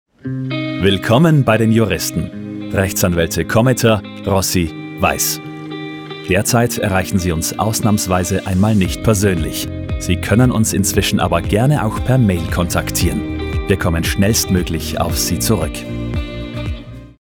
Werbesprecher
Tempo, Melodie, Intensität bestimmen den Klang.
Meine Stimme